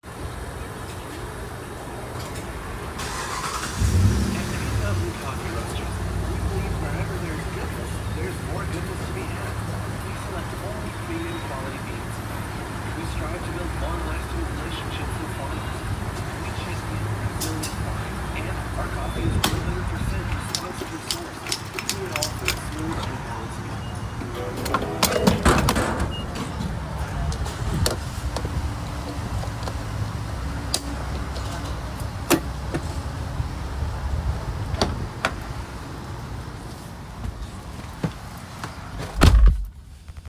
Field Recording #1
Location:Outside of Sunoco on Hempstead Turnpike
Sounds Heard: Cars passing by from the turnpike next to the station, car engines being started, cars pulling in and out of the gas station, commercial for coffee playing on gas pump, gas pump handles clicking, people talking in the background, music from cars pulling in, gas pump automatic stopping, beeping of cars alarms, car doors opening and closing